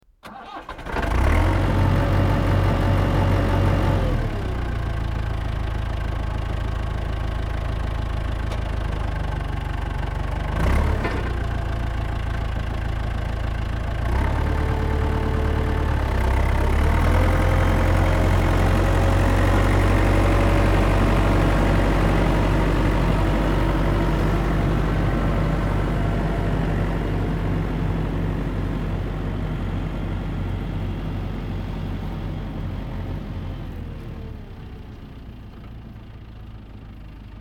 Звуки экскаватора
Звук завода и отъезда экскаватора на работу